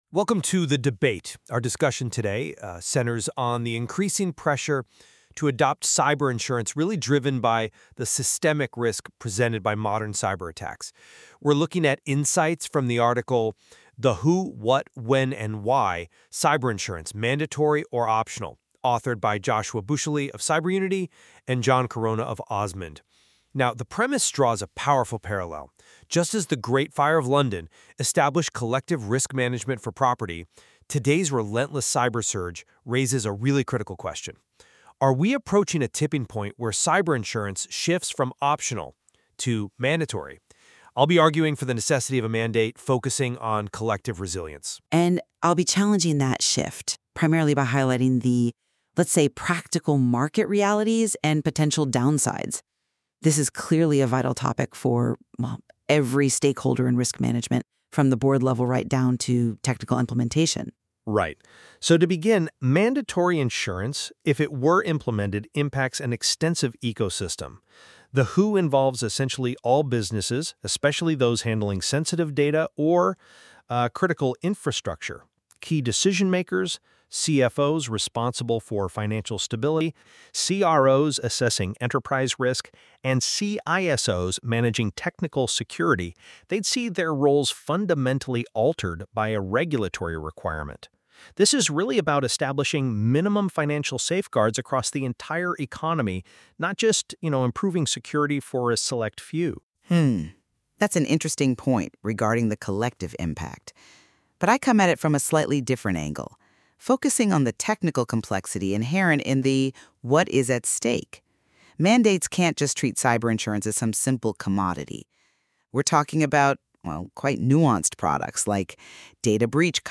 (AI Narrated)